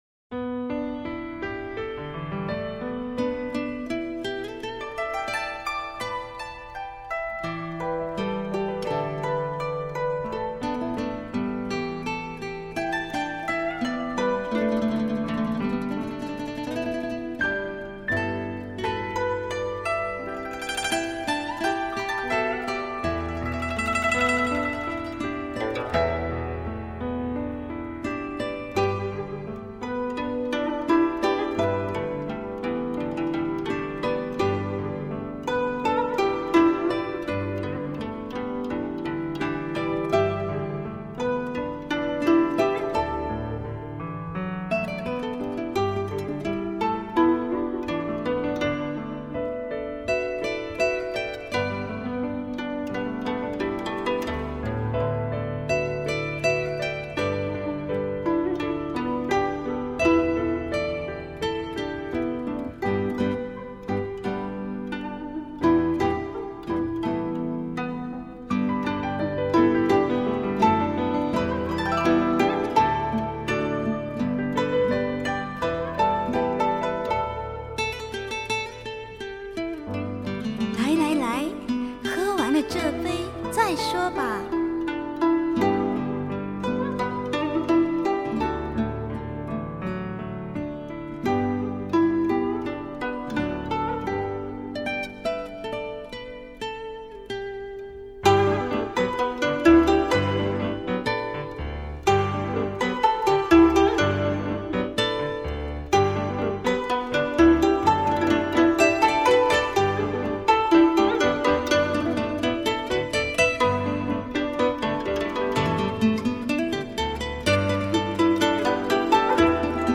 ギターとピアノの伴奏でゆっくりとメロディーを歌い上げ、途中でせりふが入ってテンポ·アップするという構成。